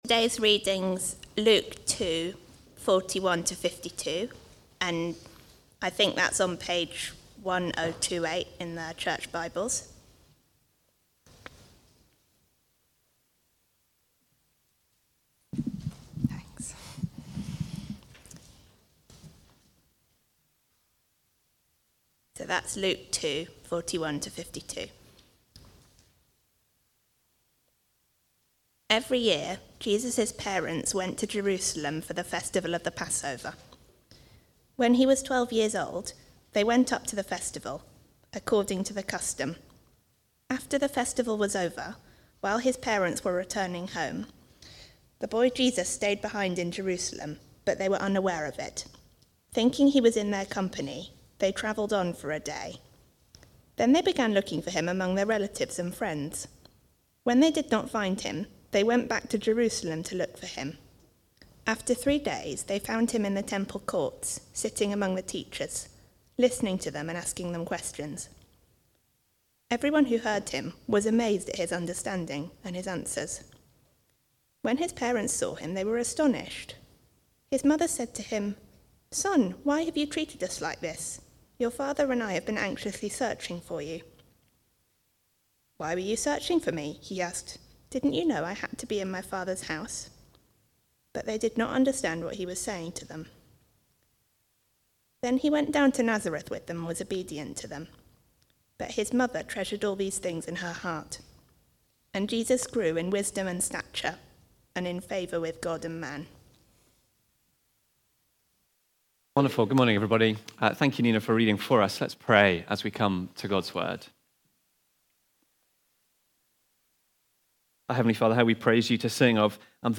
Preaching
Jesus' Life in the Body (Luke 2:41-52) from the series The Grand Miracle: Reflection on the Incarnation. Recorded at Woodstock Road Baptist Church on 01 December 2024.